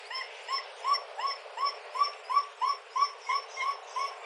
Звуки красного волка
Свист одинокого красного волка вдали от стаи